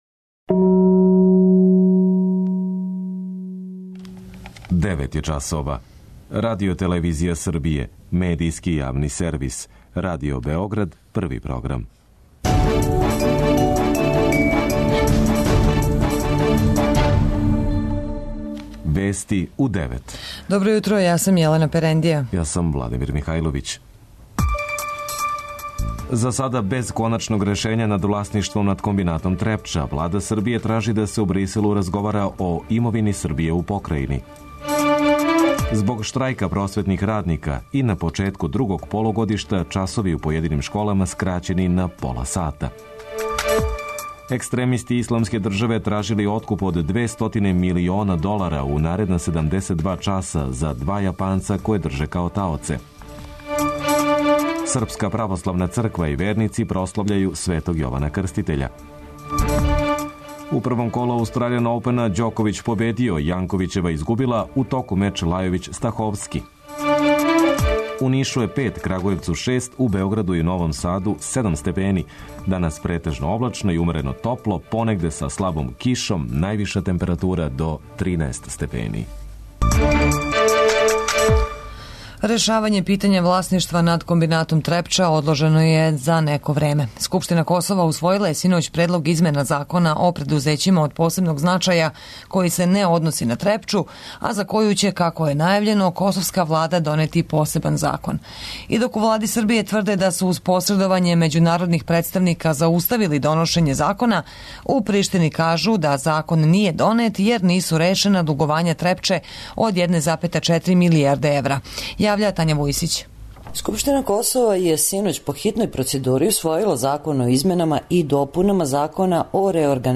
Највиша температура до 13 степени. преузми : 9.23 MB Вести у 9 Autor: разни аутори Преглед најважнијиx информација из земље из света.